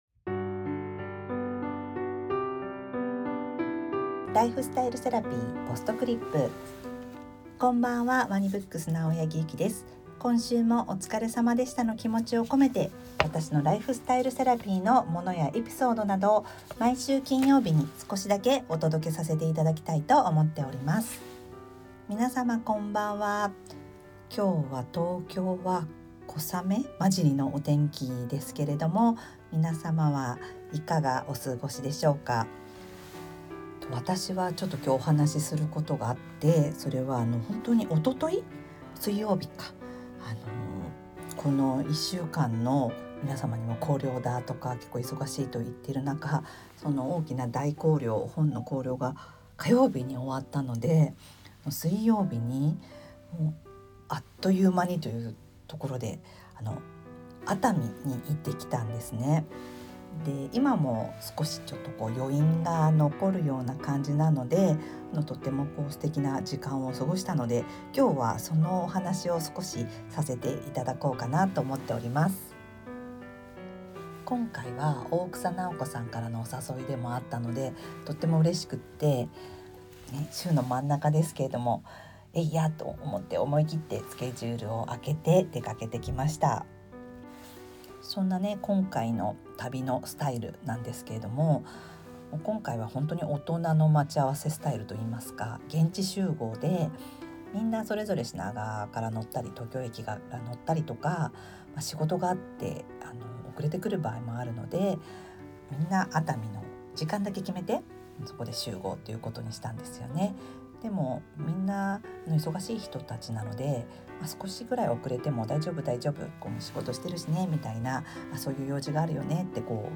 BGM／MusMus